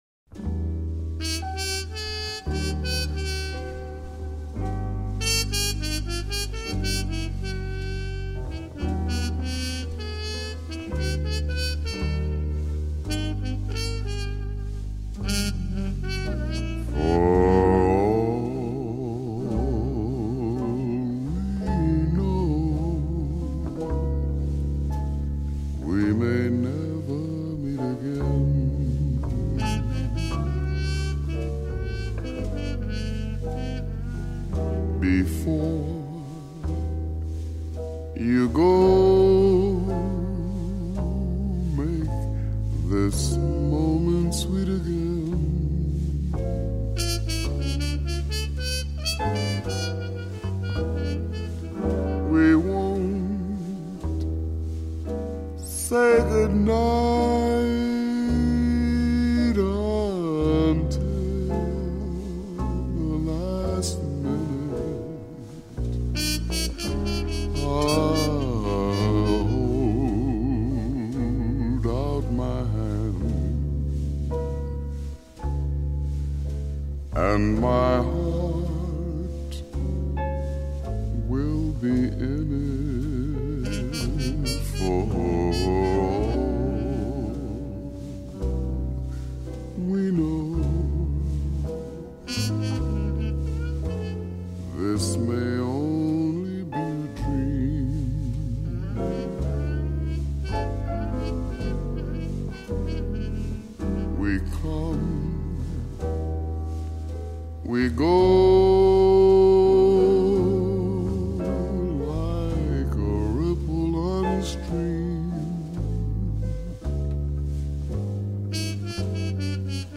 专辑风格：JAZZ